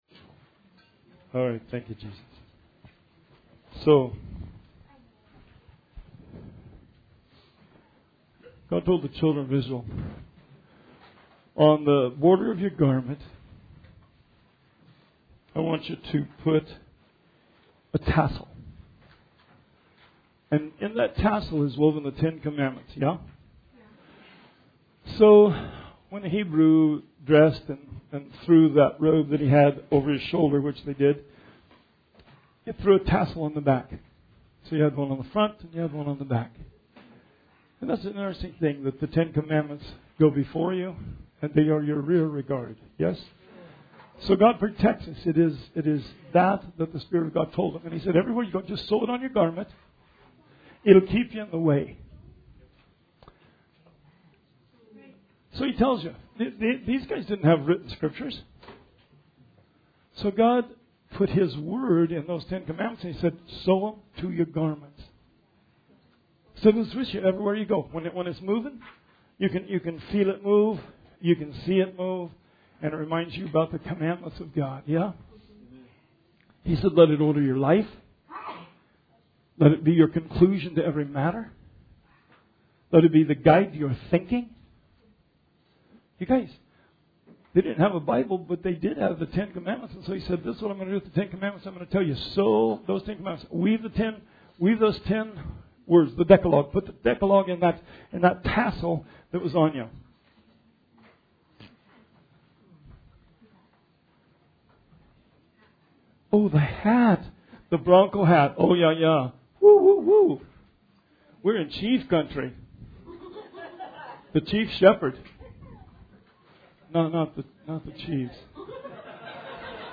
Bible Study 7/10/19